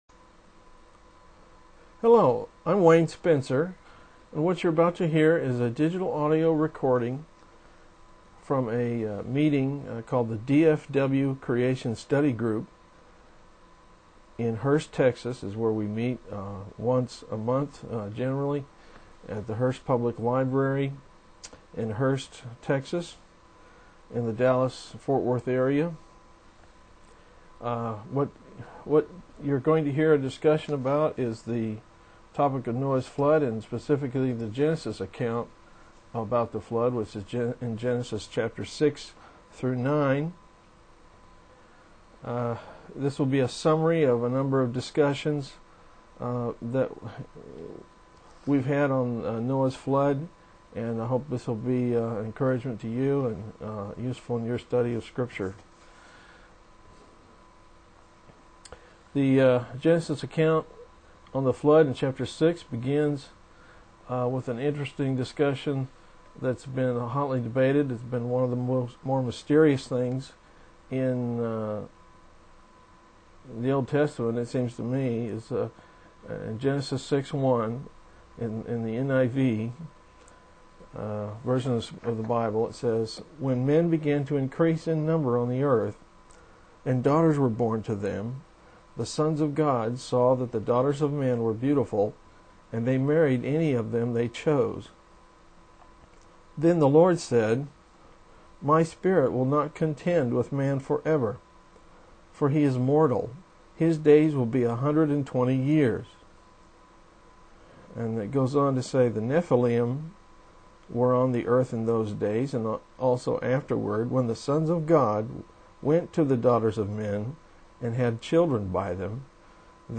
Below is a digital recording from the DFW Creation Study Group meeting of April 26, 2003.